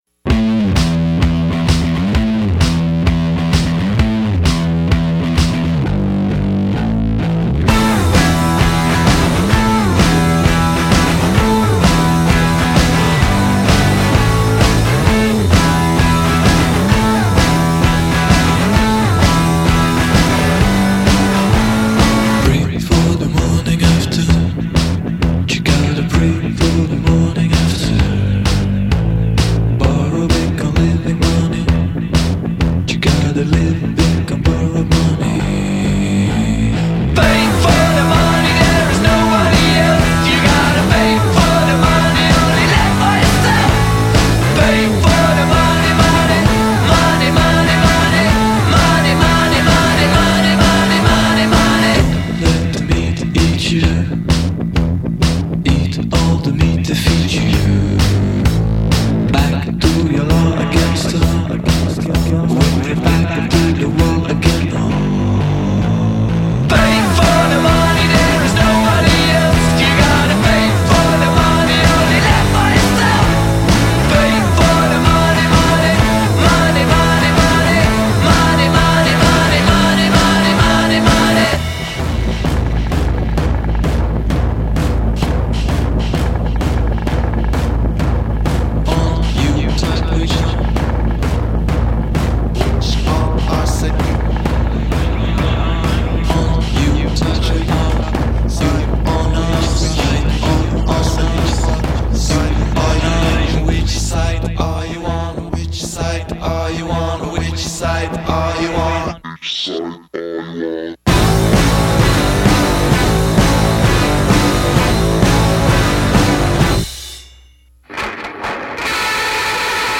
Garage rockers